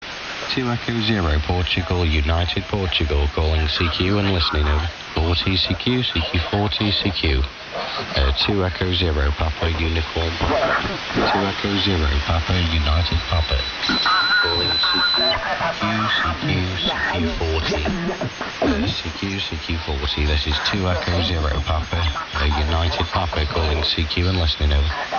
Recorded using Kenwood VM TS-950SDX @ 0 - 6000Hz
To appreciate the full fidelity of the enhanced frequency response.